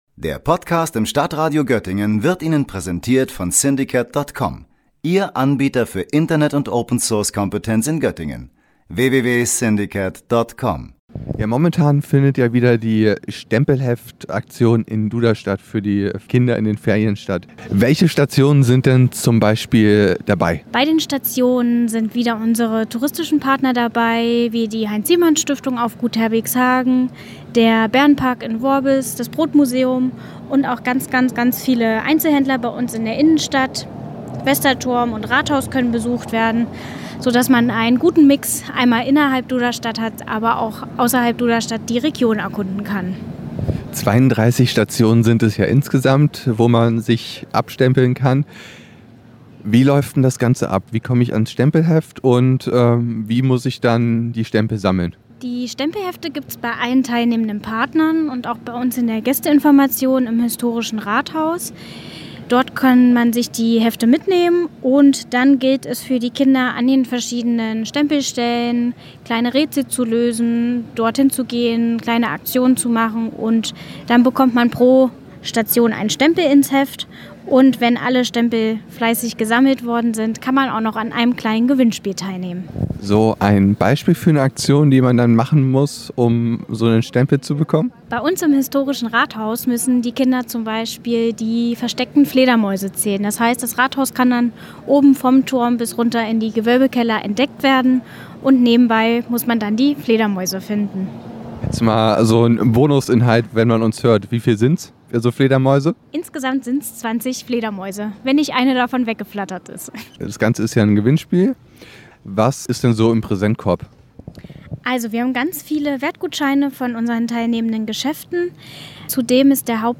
Minirätsel für einen Stempel lösen – das ist das Konzept des Duderstädter Stempelheftes, einer Aktion für Kinder. Im Gespräch